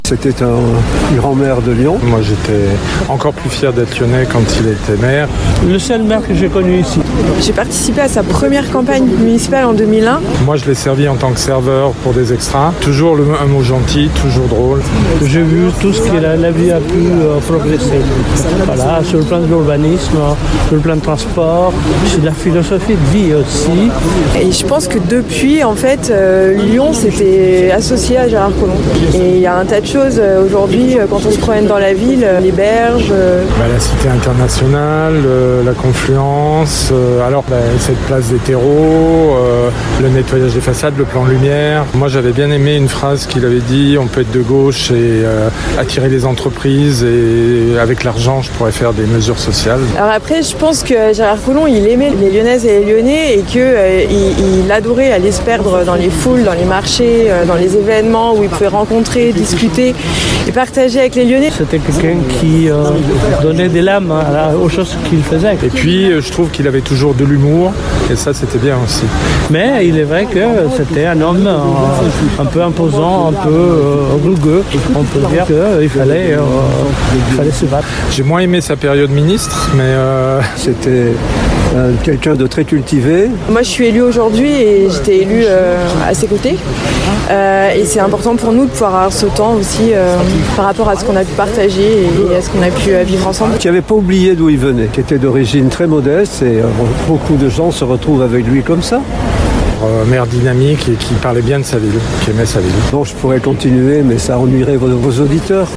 Ecoutez l’hommage des Lyonnaises et des Lyonnais à leur maire disparu….
micro-trottoir
MICRO-TROTTOIR-SUR-COLLOMB.mp3